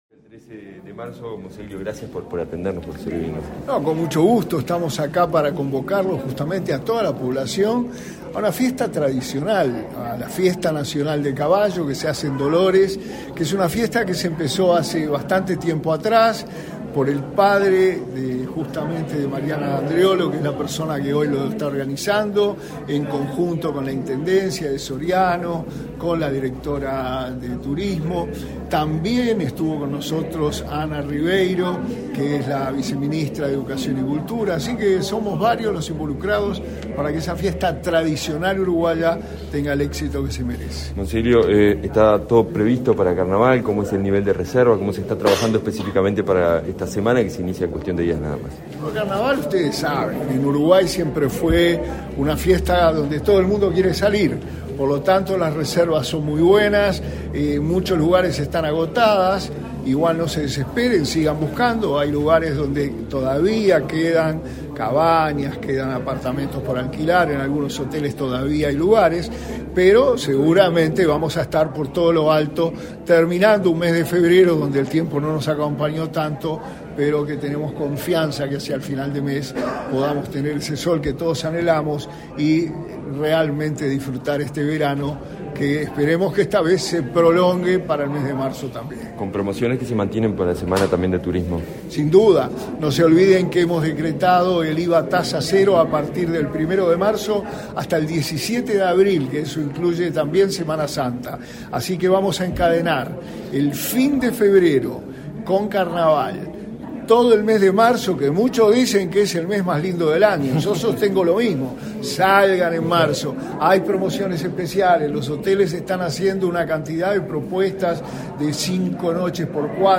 Declaraciones a la prensa del subsecretario de Turismo, Remo Monzeglio
En el marco del Mes de las Tradiciones Criollas, se realizará la 33.ª edición de la Fiesta Nacional del Caballo. Se desarrollará del 11 al 13 de marzo, en la ciudad de Dolores. En la presentación del evento, que será apoyado por los ministerios de Turismo y de Cultura, este 22 de febrero el subsecretario Remo Monzeglio efectuó declaraciones a la prensa.